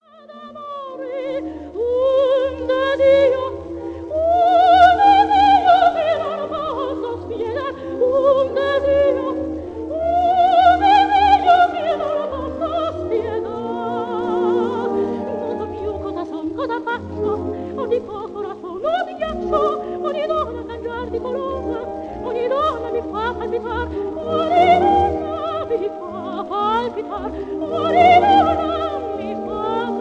soprano
Recorded inThe Kingsway Hall, London on 14 October 1947